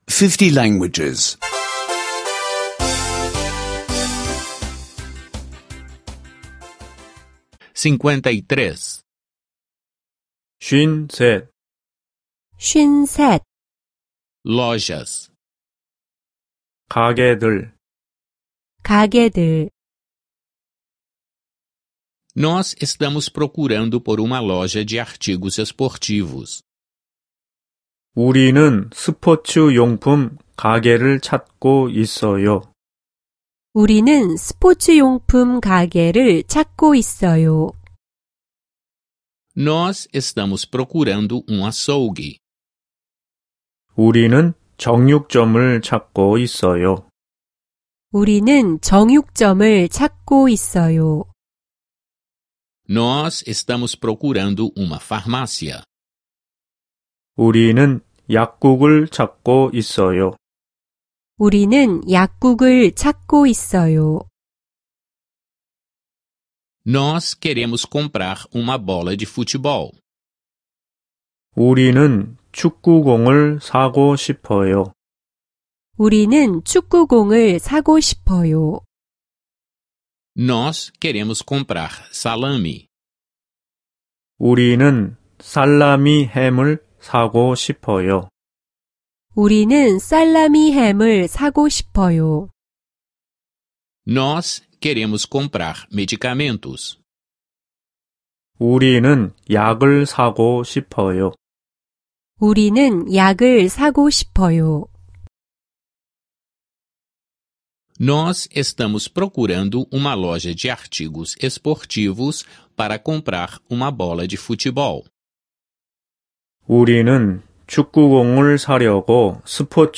Aulas de coreano em áudio — download grátis